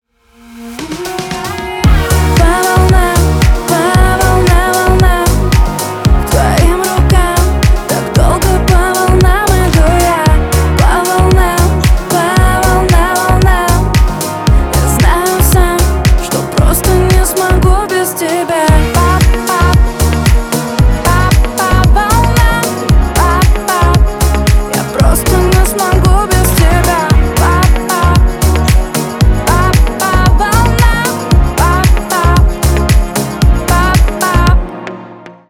• Качество: 320 kbps, Stereo
Поп Музыка
клубные